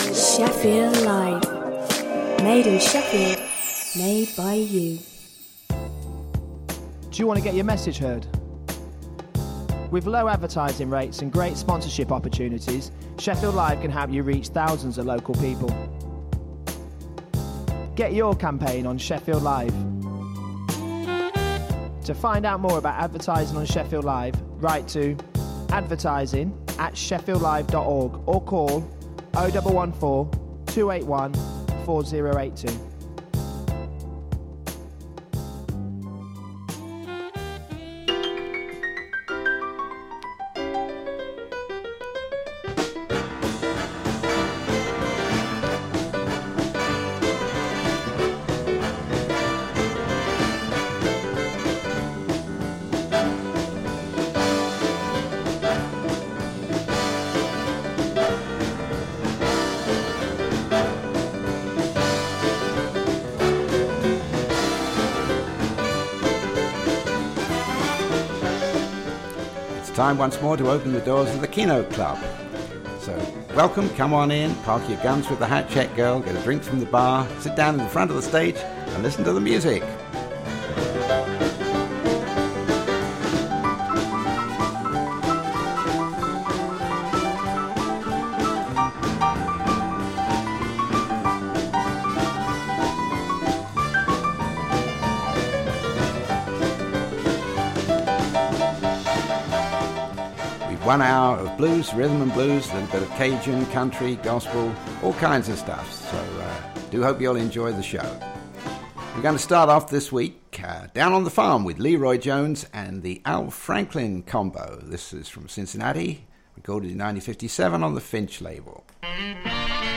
Shefffield Live presents… One hour of the best and the rarest in blues and rhythm ‘n’ blues